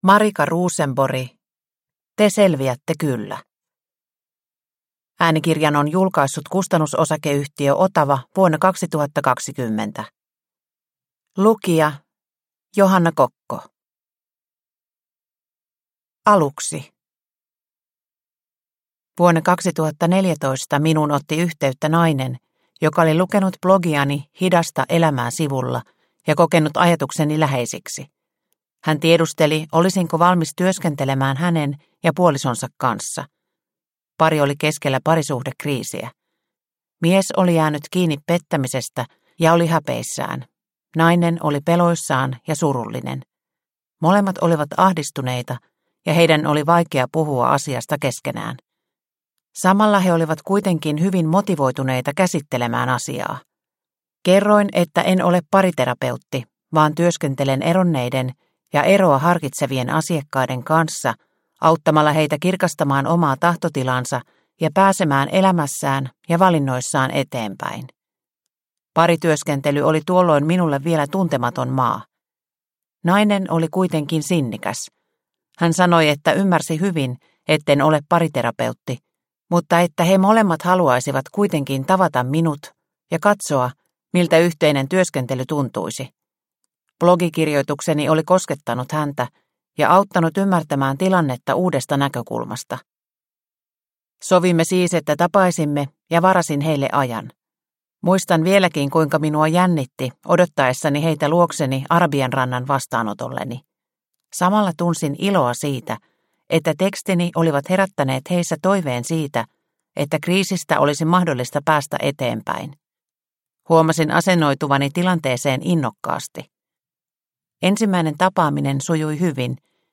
Te selviätte kyllä – Ljudbok – Laddas ner